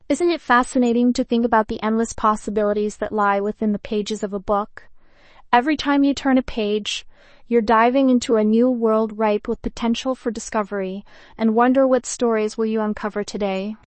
female_normal.mp3